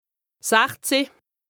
2APRESTA_OLCA_LEXIQUE_INDISPENSABLE_HAUT_RHIN_97_0.mp3